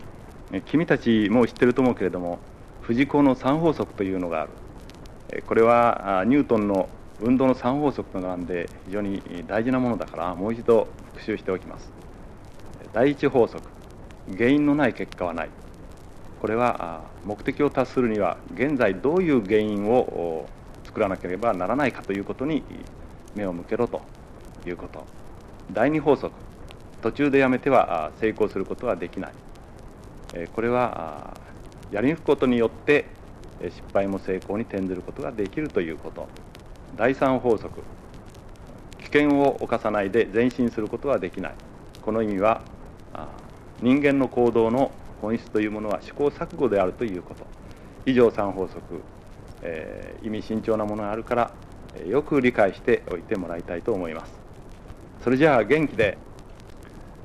声のアルバム（ソノシート）